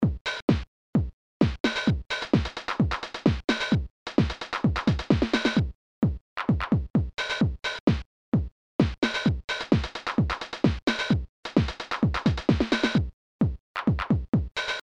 808 / 909 FREE VSS KIT
Processed and recorder with a VSS30.
A little gift from us !Roland TR-808 and TR-909 drum machines